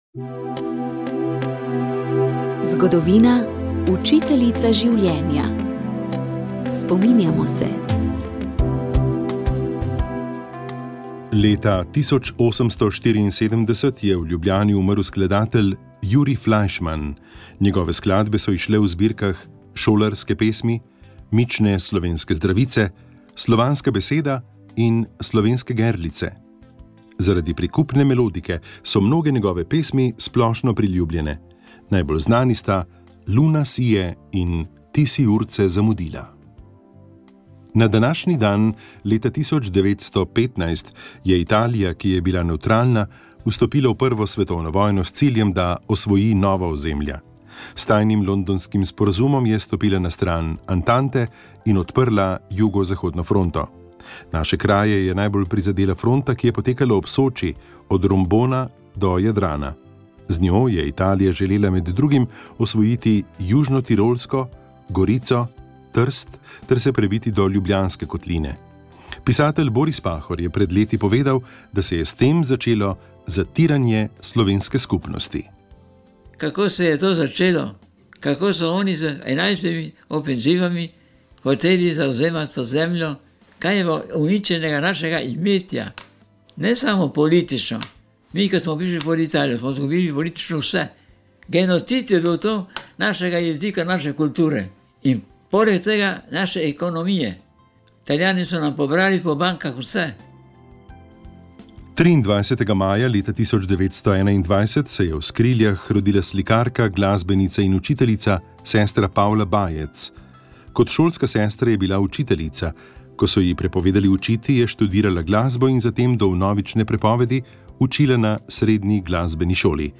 družba duhovnost koronavirus odnosi pogovor